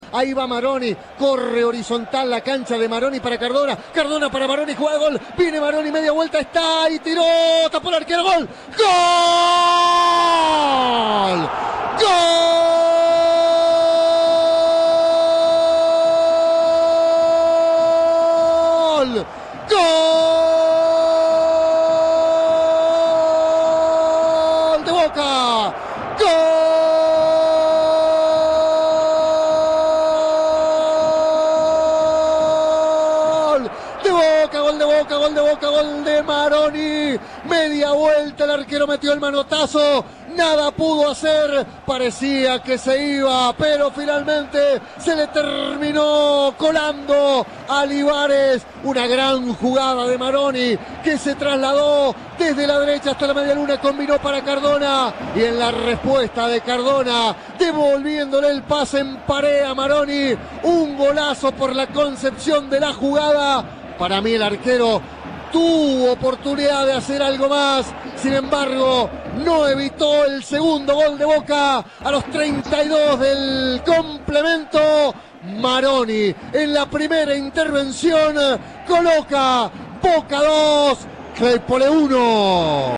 Reviví los relatos de los goles de la victoria del "Xeneize"